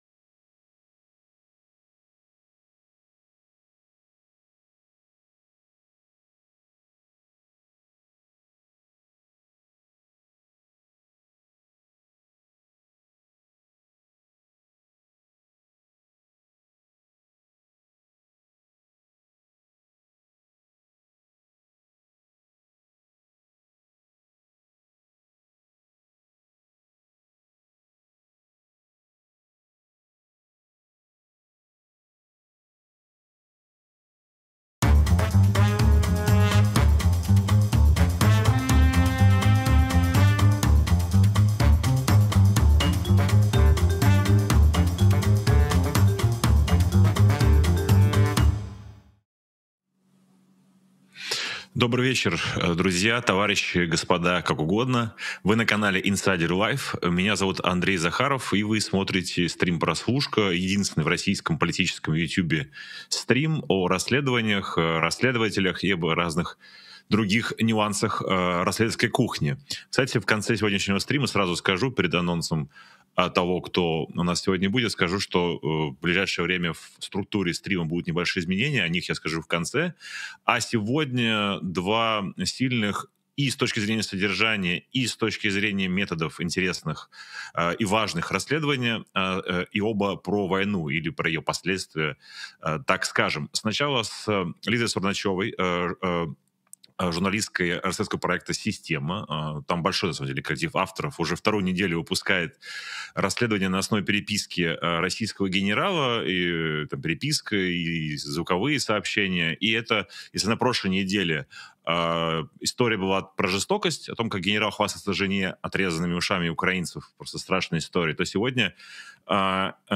журналист-расследователь